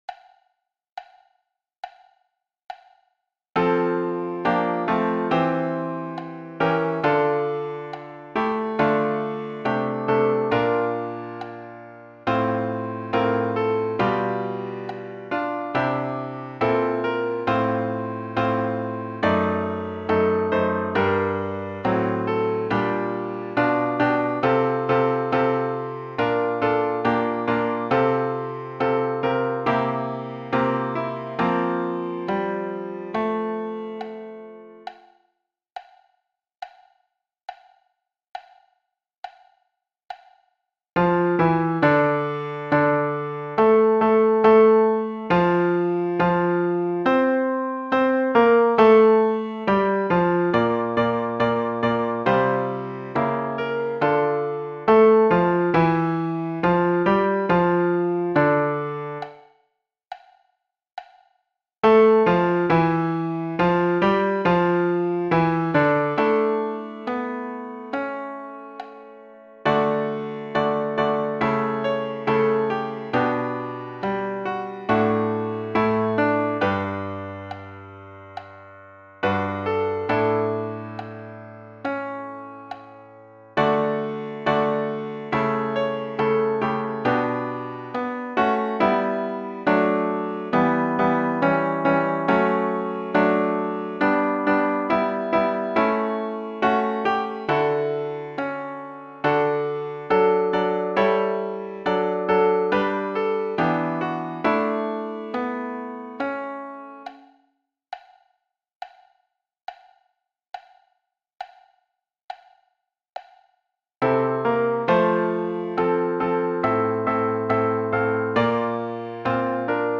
HAuuaGXhTbp_3.-Credo-4-parties-égales-mp3.mp3